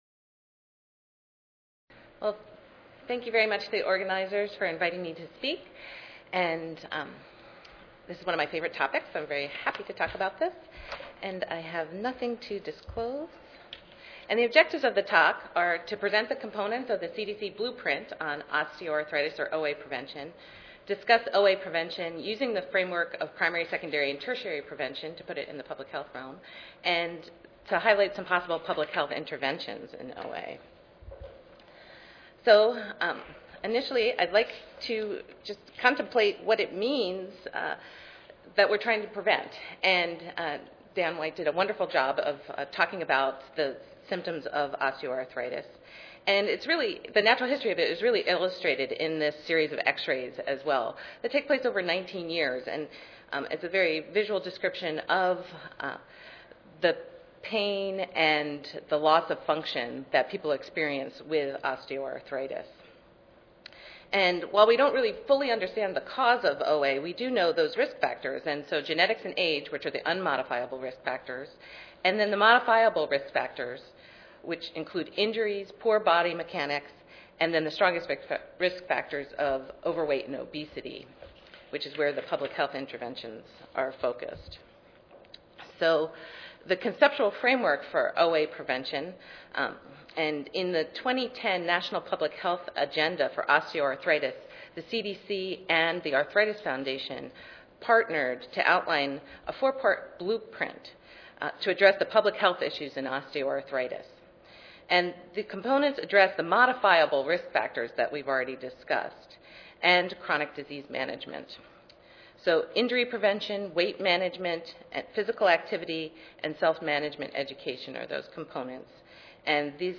Recorded Annual Meeting Presentations (RAMP) are now available for purchase.